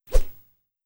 Woosh 03.wav